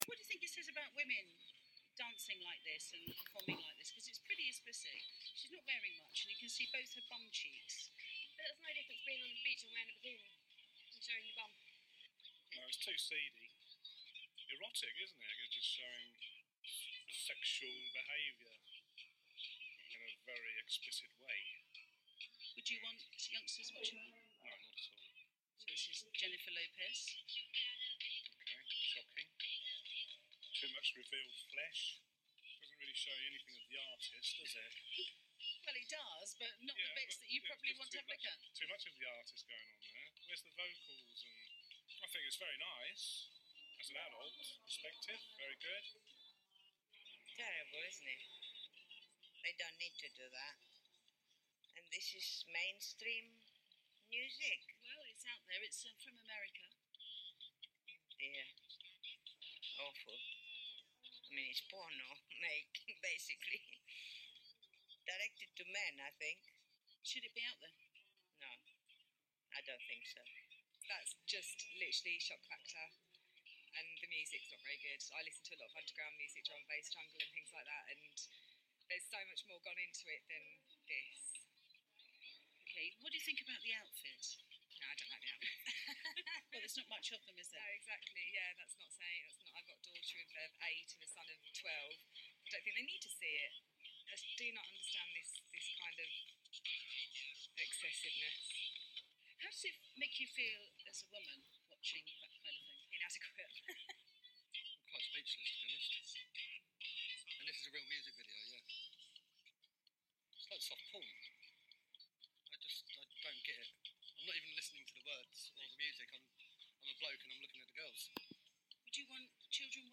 Radio Cambridge interview